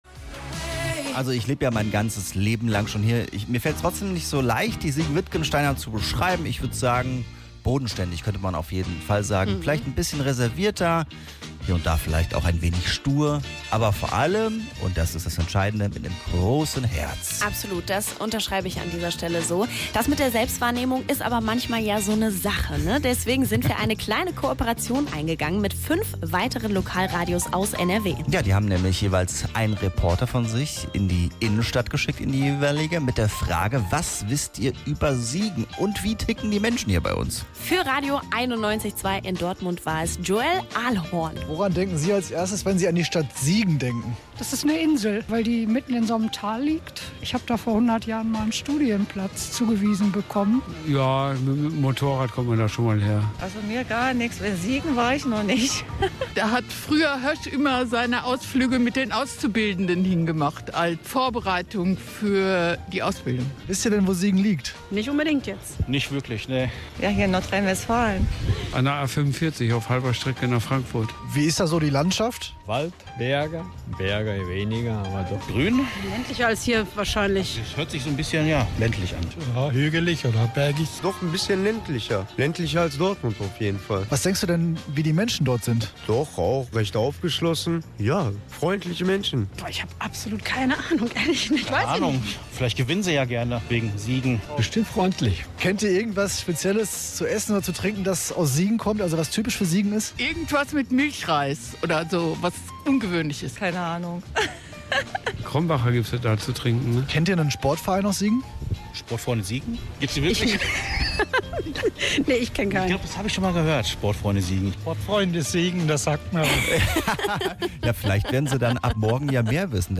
Mit Straßenumfragen aus Köln, Dortmund, Essen, Münster und Leverkusen.